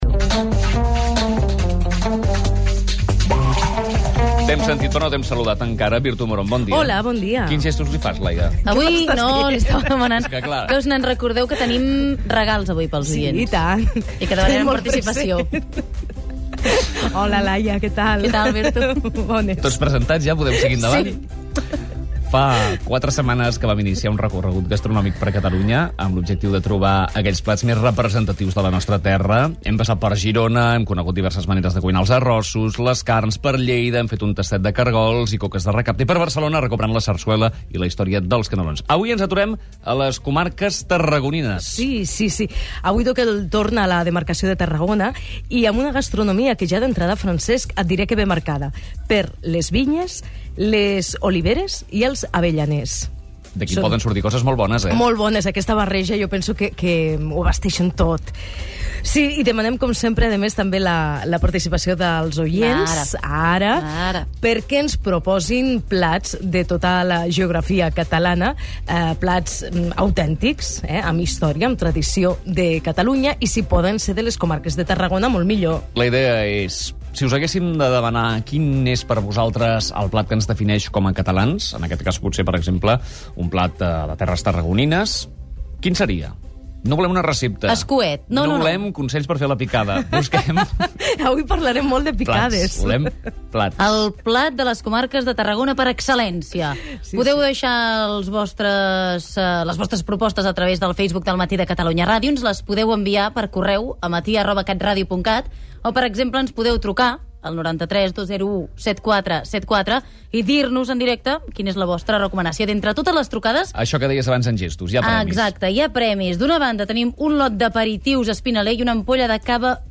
S'intercalen les opinios de l'audiència i al final es concedeix un premi entre les persones participants.
Info-entreteniment